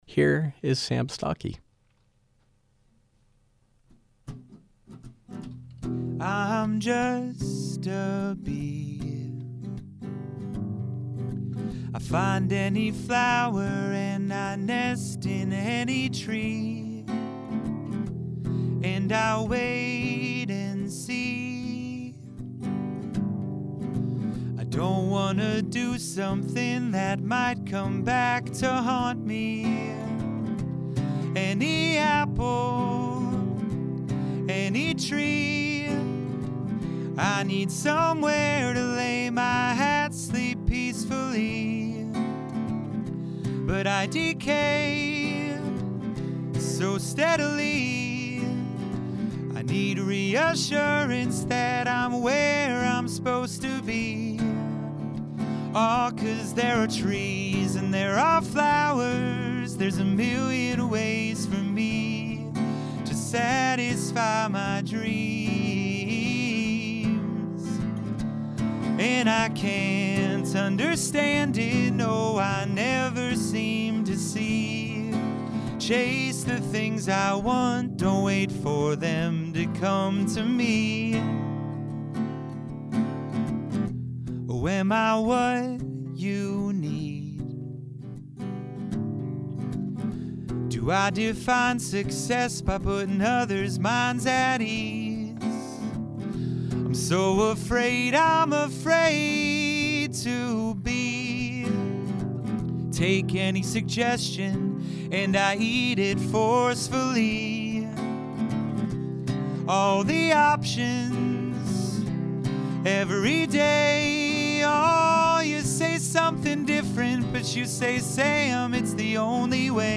Live music
singer/songwriter